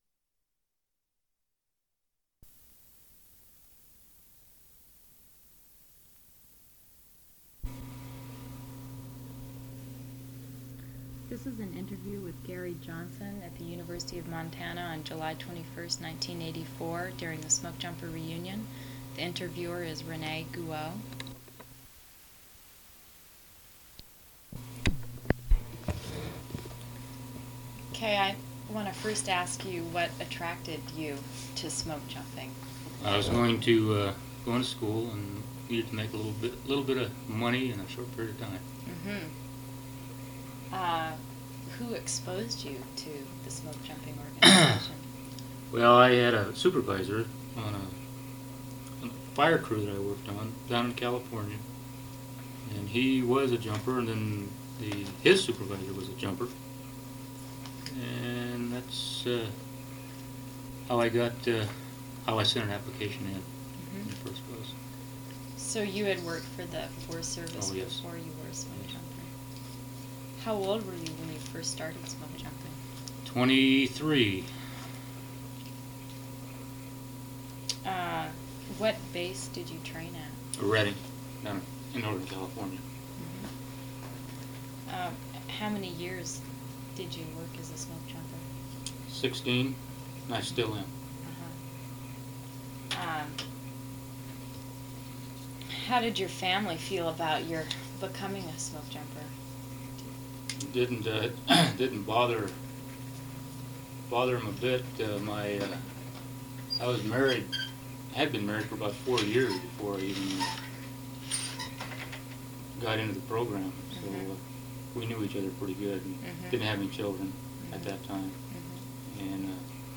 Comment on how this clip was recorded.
1 sound cassette (65 min.) : analog